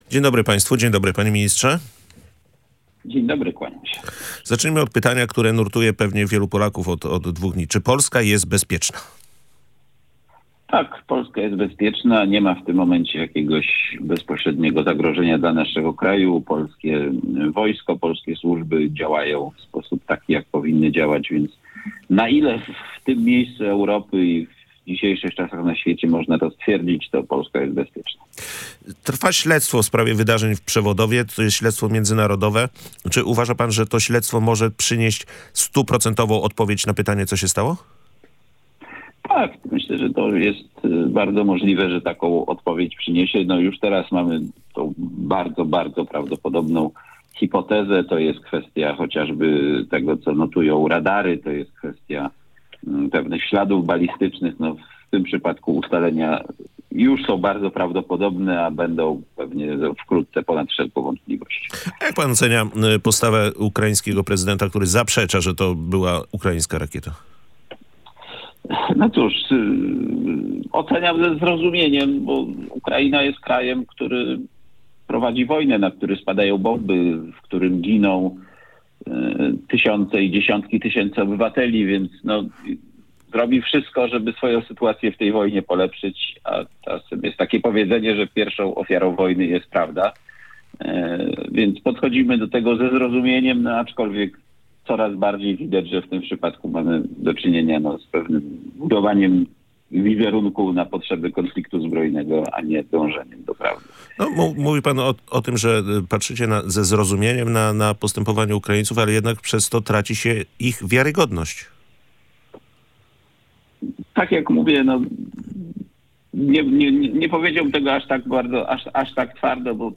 Takie działanie oznaczałoby przystąpienie Polski do wojny – mówił w Radiu Gdańsk Marcin Horała, sekretarz stanu w Ministerstwie Funduszy i Polityki Regionalnej.